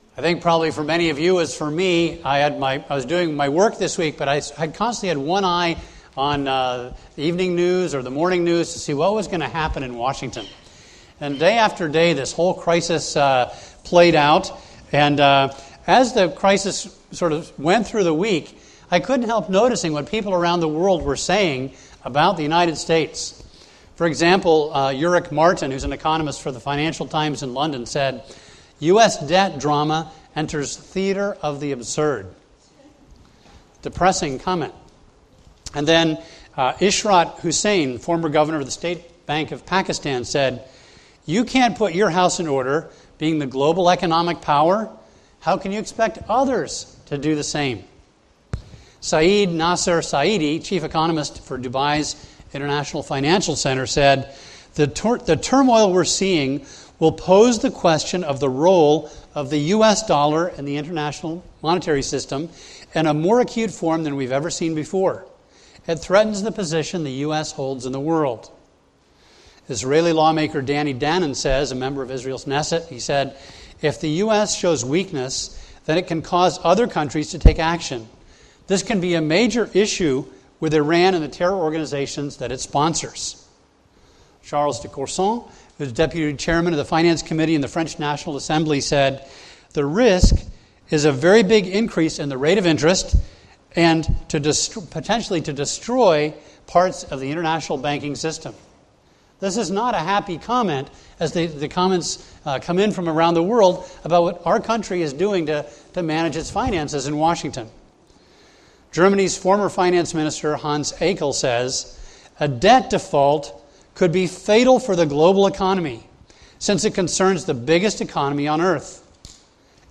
A message from the series "End Times."